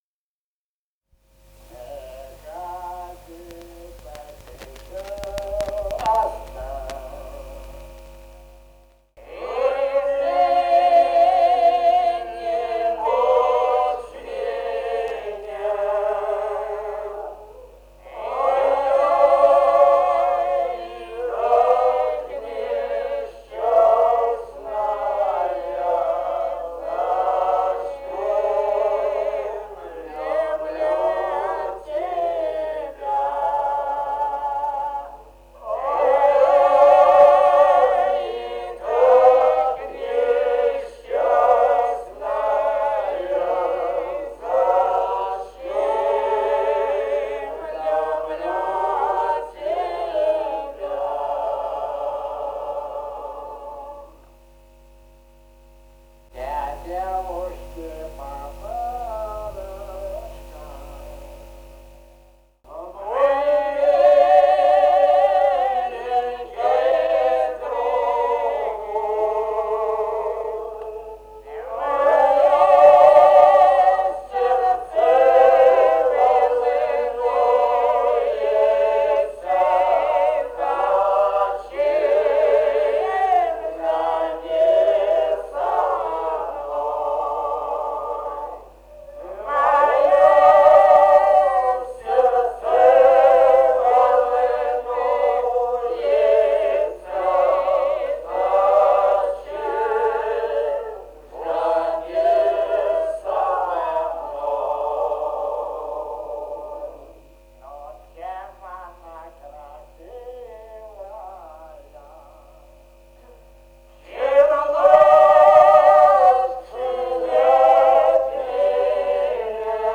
полевые материалы
«Не ходи так часто» (лирическая).
Грузия, с. Гореловка, Ниноцминдский муниципалитет, 1971 г. И1309-17